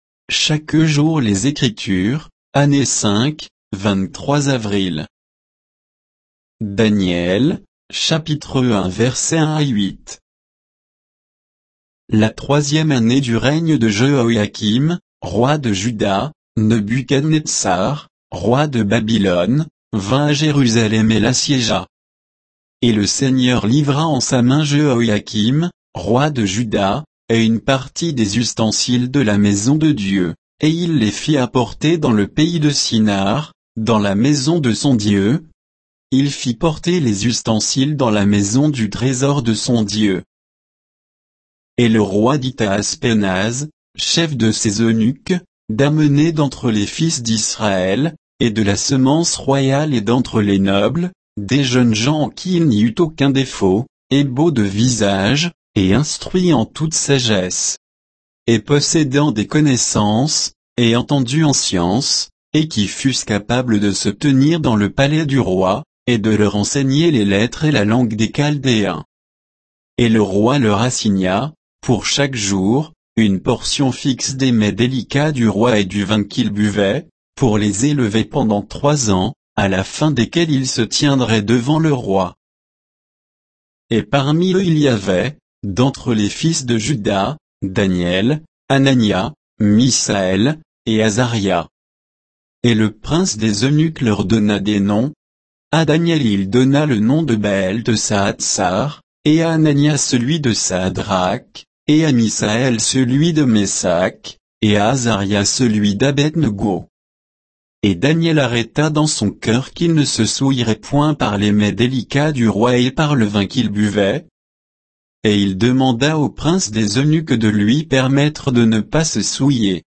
Méditation quoditienne de Chaque jour les Écritures sur Daniel 1